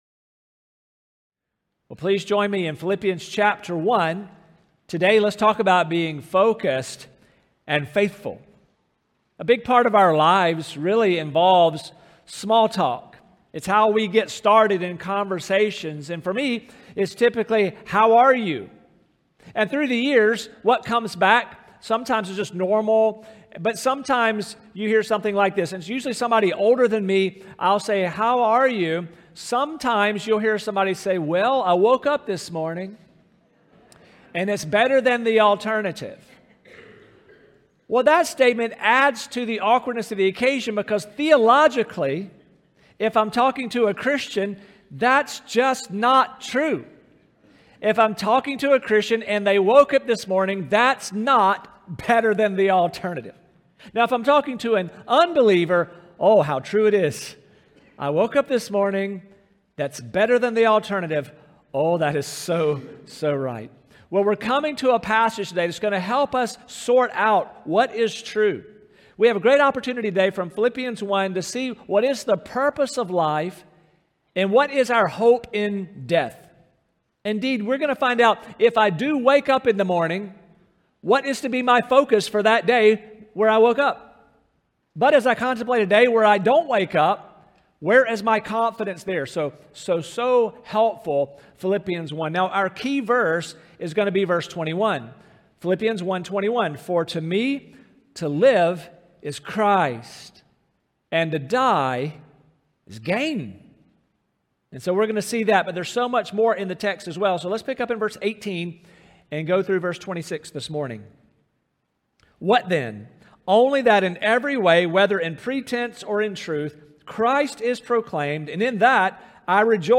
Sermons | Staples Mill Road Baptist Church